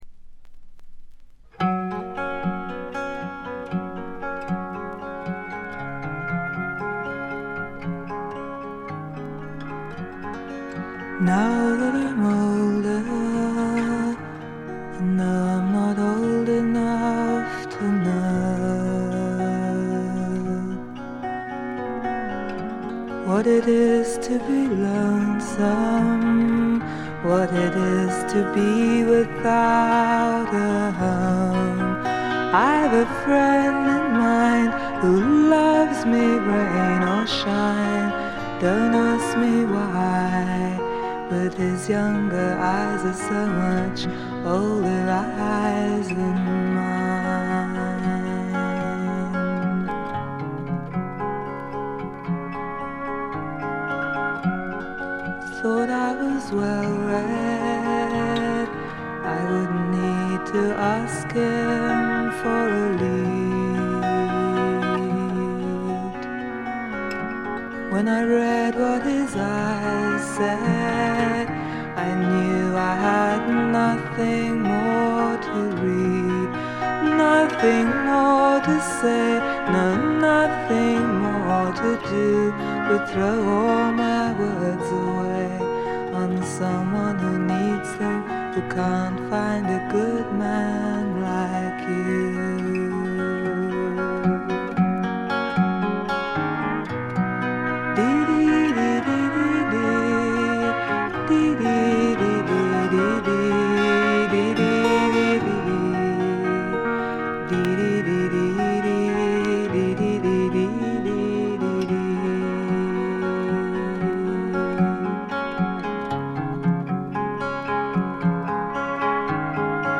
A4冒頭とB1中盤で2連のプツ音。
試聴曲は現品からの取り込み音源です。
Pedal Steel Guitar